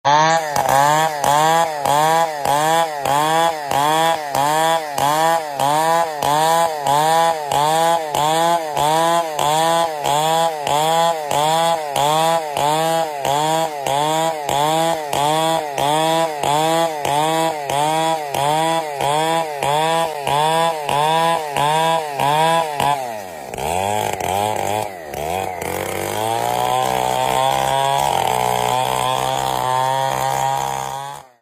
Nada Dering Alarm Suara Mesin Senso
Suara mesin senso yang nyaring dan khas ini bikin HP kamu langsung mencuri perhatian.
nada-dering-alarm-suara-mesin-senso.mp3